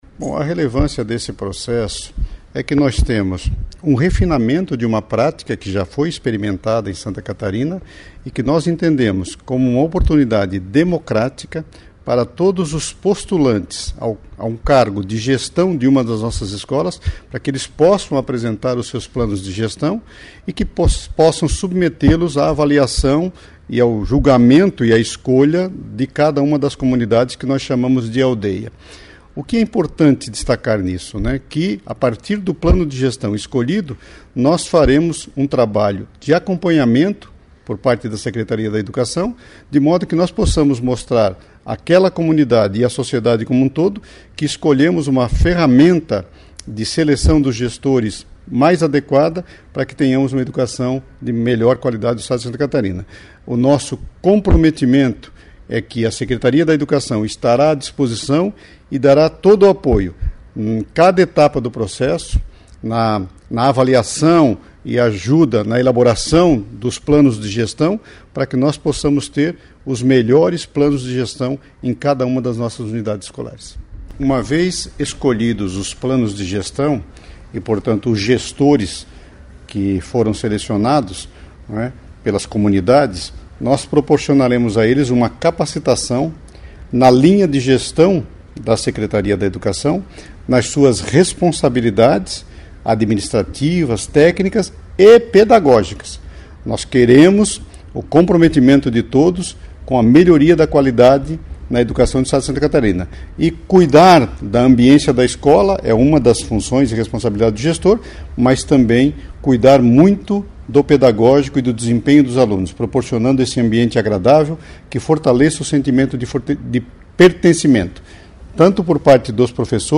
Secretário de Estado da Educação, Natalino Uggioni.
9-8-Mensagem-Sec-Plano-de-Gestão-Rádios.mp3